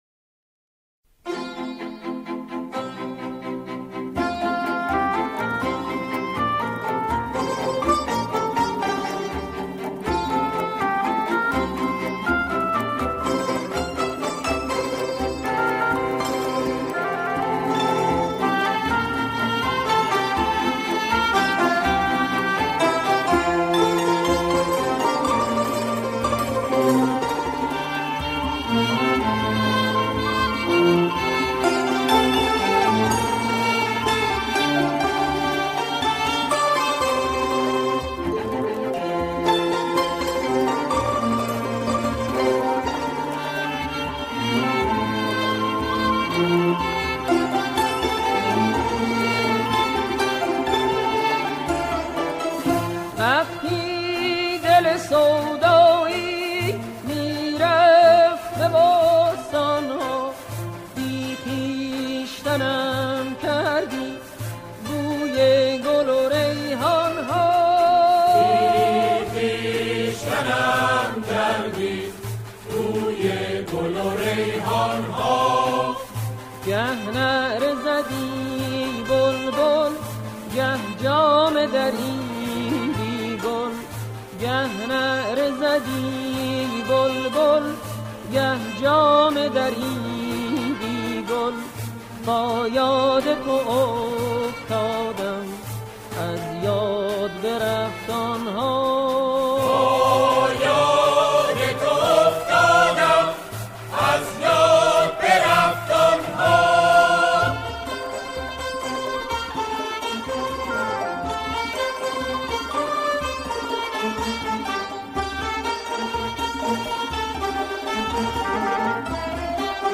آنها در این قطعه، شعری عارفانه را همخوانی می‌کنند.